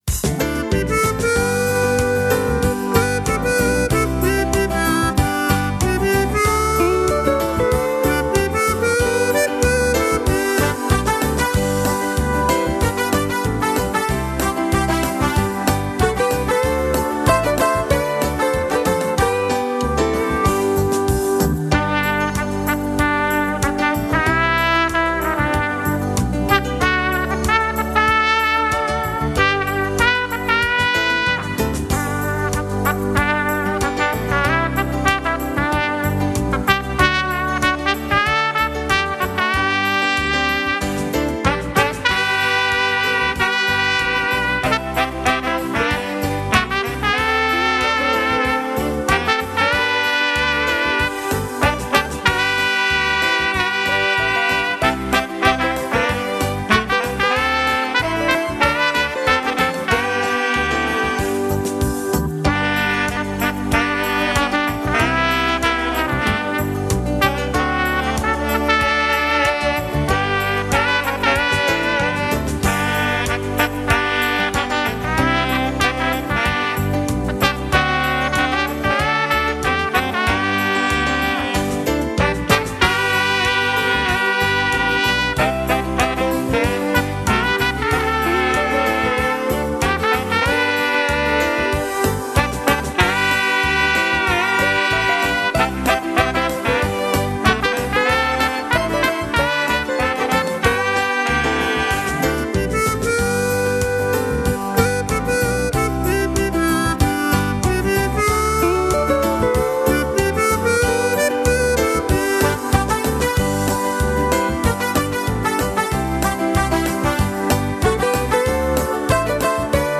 Power in the blood (country version))
Sax
Kategorie: Instrumental